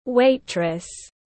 Bồi bàn nữ tiếng anh gọi là waitress, phiên âm tiếng anh đọc là /ˈweɪtrəs/.
Waitress /ˈweɪtrəs/
Waitress.mp3